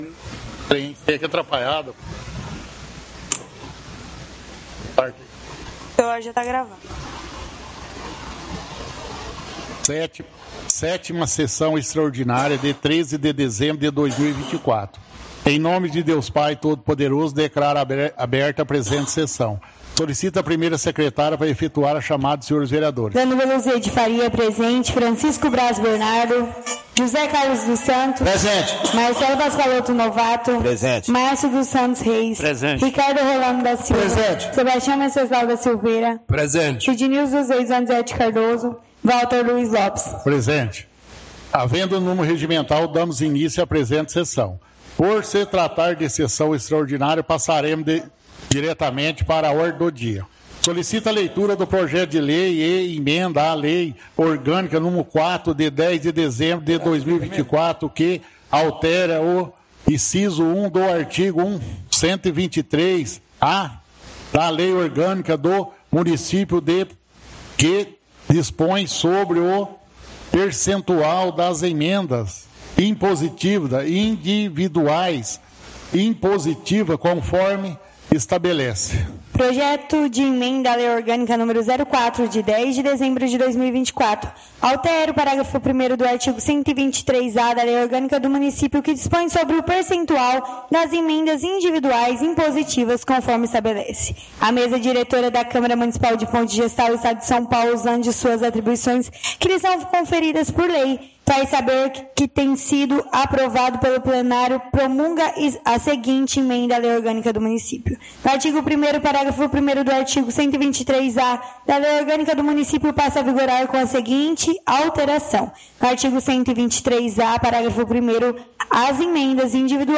Áudio da 7ª Sessão Extraordinária – 13/12/2024